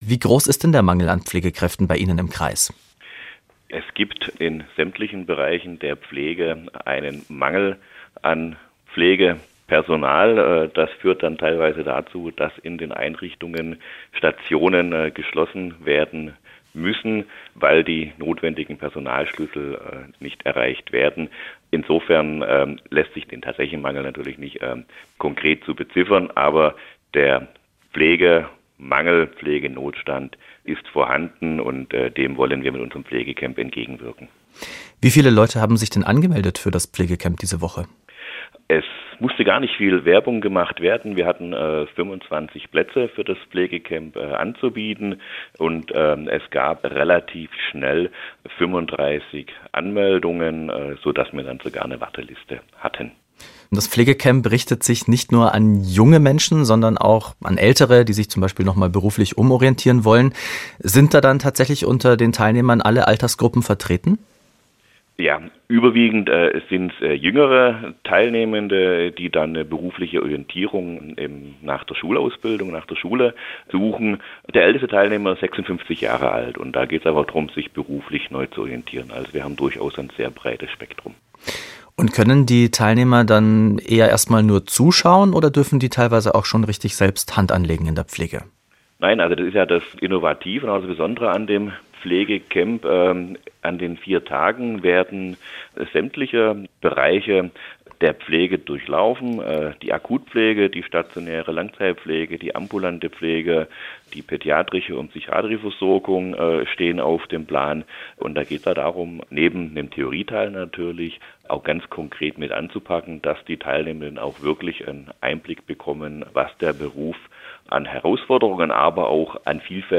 Der ist Dezernent